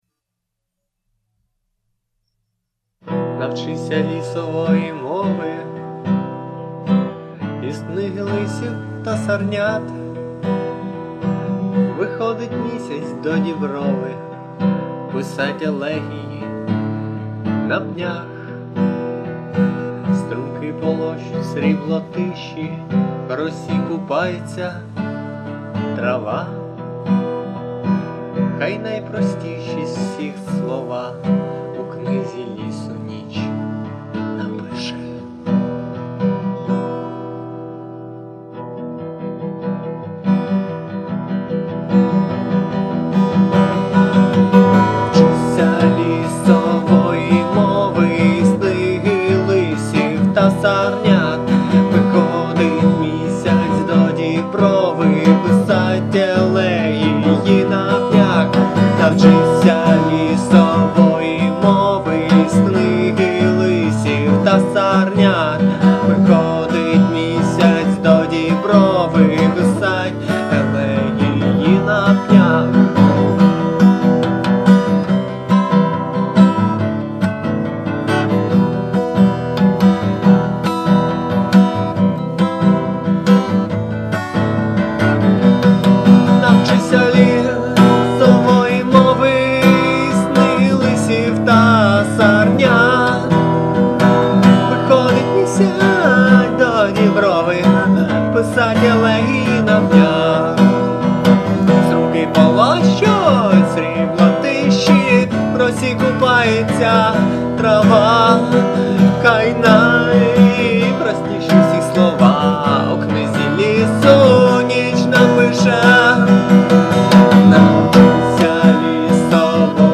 акустика